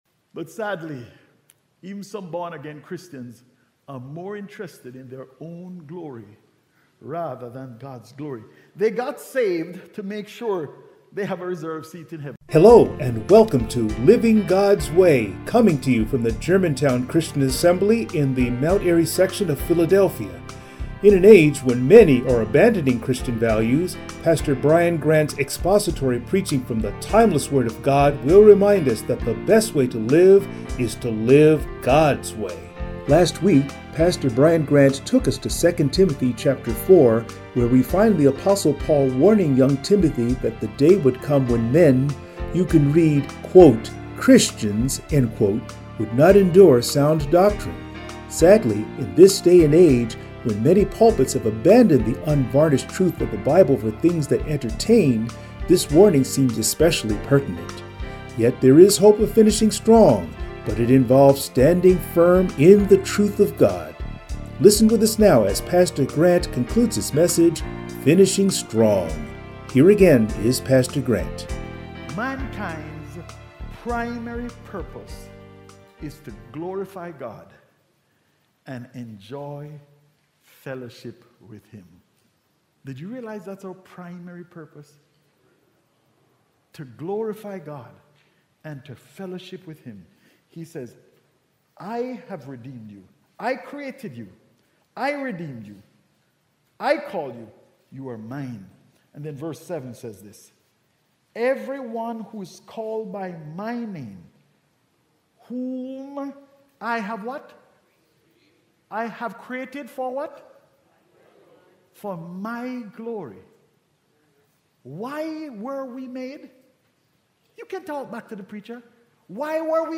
Passage: 2 Timothy 4:1-18 Service Type: Sunday Morning